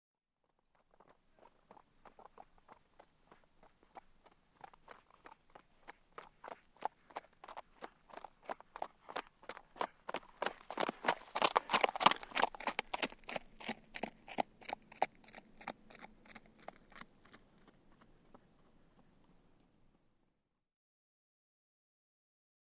دانلود آهنگ اسب 84 از افکت صوتی انسان و موجودات زنده
دانلود صدای اسب 84 از ساعد نیوز با لینک مستقیم و کیفیت بالا
برچسب: دانلود آهنگ های افکت صوتی انسان و موجودات زنده دانلود آلبوم انواع صدای شیهه اسب از افکت صوتی انسان و موجودات زنده